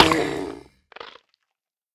Minecraft Version Minecraft Version snapshot Latest Release | Latest Snapshot snapshot / assets / minecraft / sounds / mob / turtle / death1.ogg Compare With Compare With Latest Release | Latest Snapshot